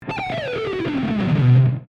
If you look carefully – you’ll see the only difference between the two is the F# and the G.   Both notes sound good against E minor, so if we combine them we get a six- note scale (E, F#, G, A, B, D).
Sextuplet descending
* Descending Picking Note: I play this with a modified sweep picking pattern
For those of you who are interested, tone on this recording was the same AU Lab/Apogee/FNH combination that I detailed here:
sextuplet-descending.mp3